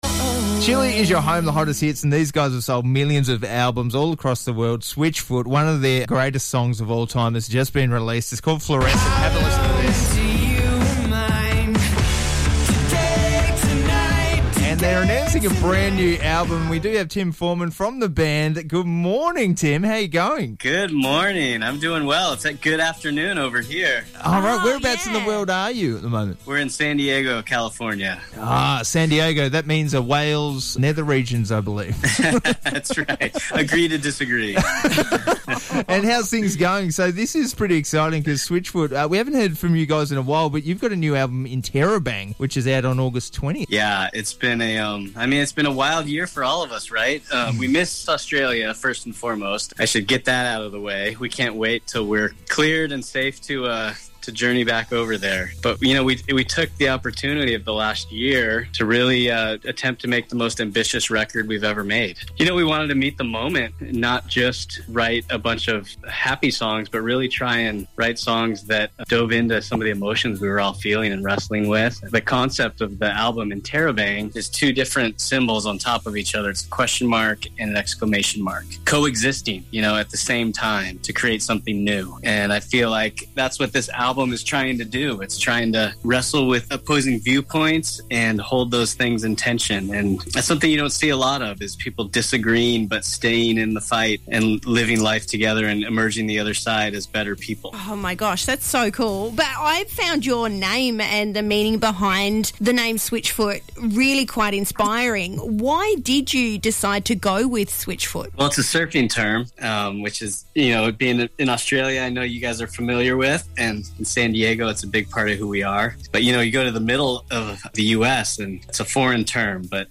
Tim Foreman from Switchfoot chats 'Interrobang'